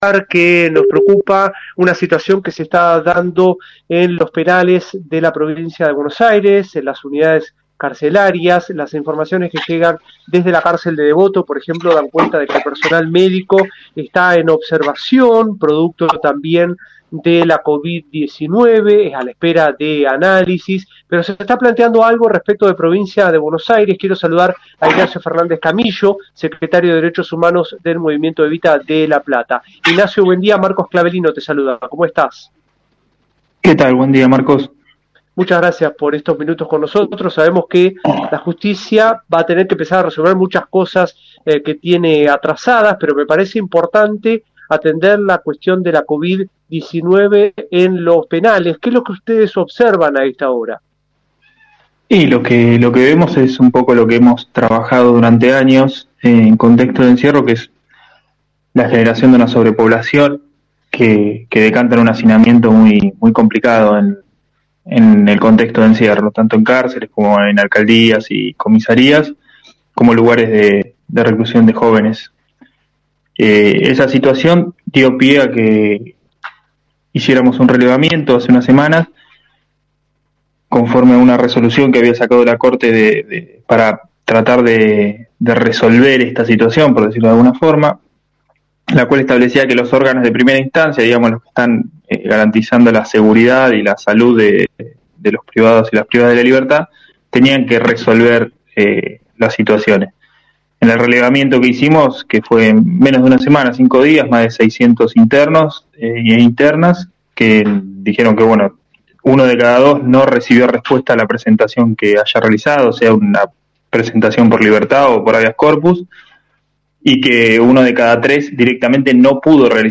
En diálogo con radio Universidad